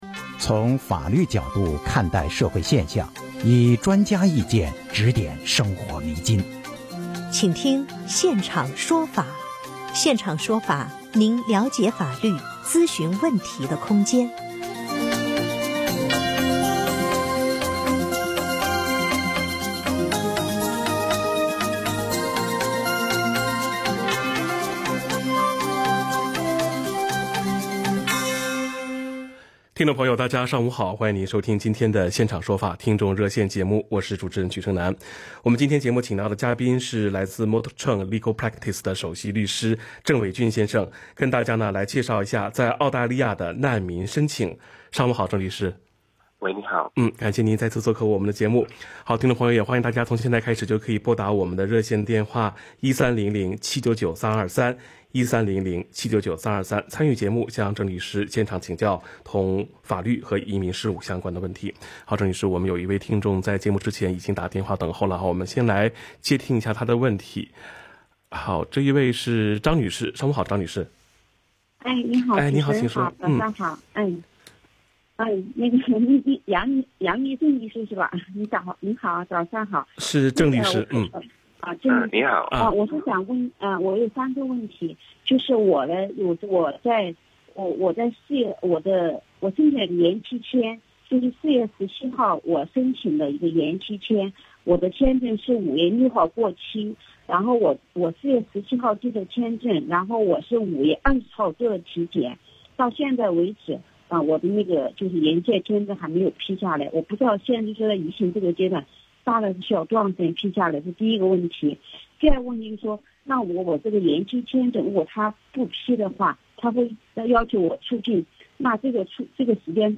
legal_talkback_refugee.mp3